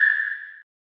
sonar6.mp3